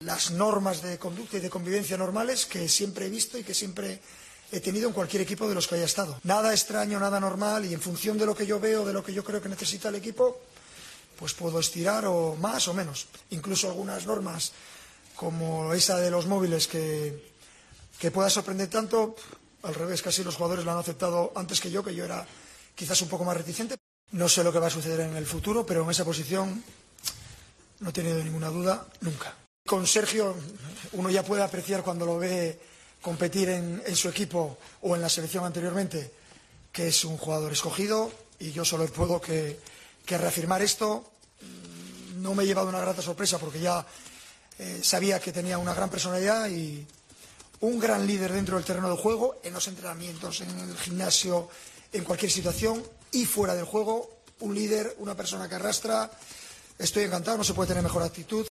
El seleccionador de España habló en la rueda de prensa previa al partido frente a Inglaterra y aclaró que "no tiene dudas sobre la portería" y destacó el "liderazgo" de Ramos en la plantilla.